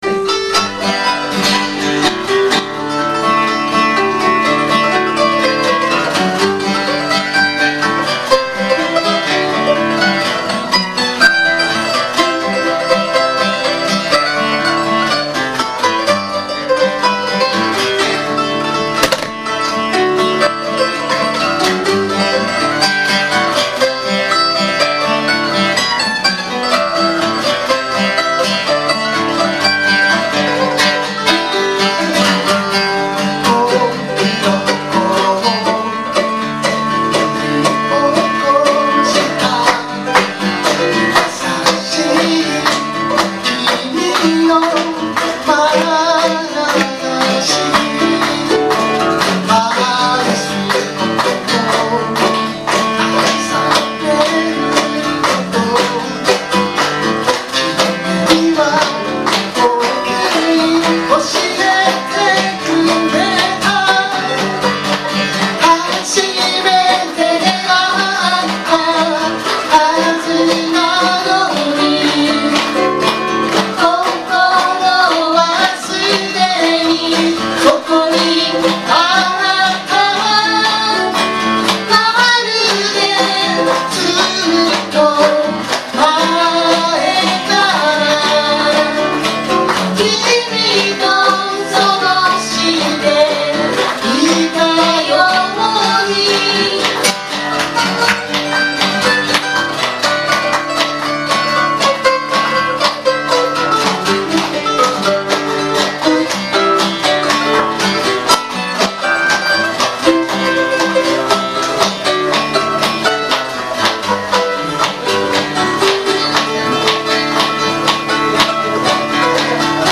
Bluegrass style Folk group
Key of G
企画名: アコースティックライブ
録音場所: ふじみ野ふぃるもあ
ボーカル、マンドリン
リードボーカル、ギター
コーラス